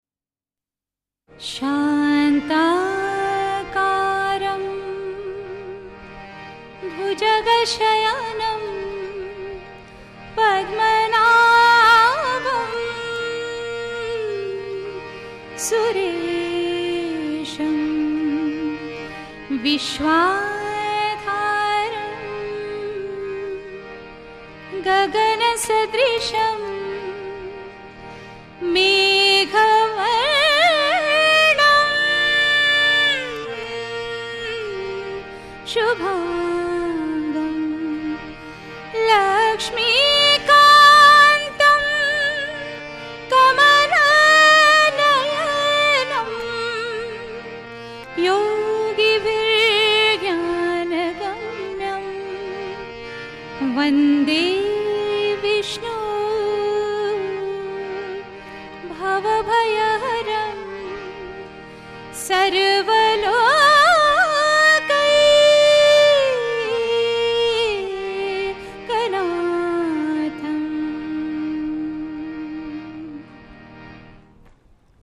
Live Bhajan program
at Jagadeeswara Temple Mumbai.
Bhajan